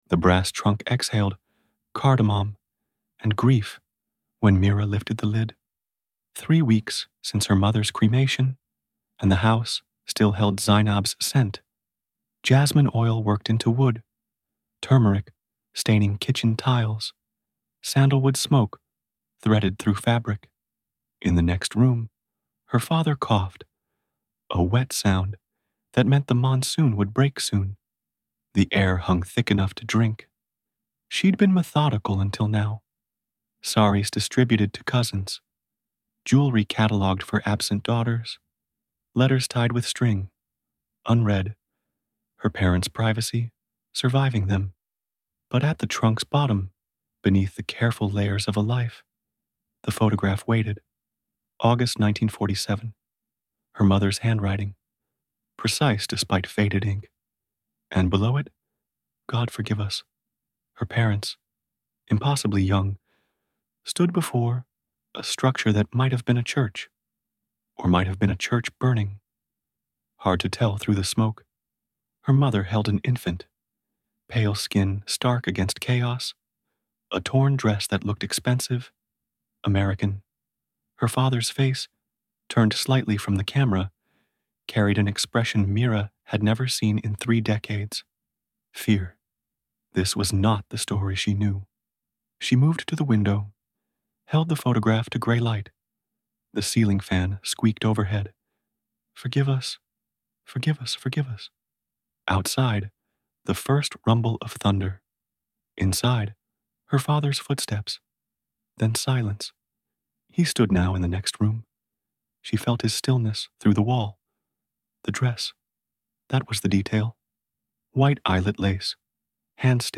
Narrated by Thomas (AI voice) • Duration: ~10 minutes
full_audiobook.mp3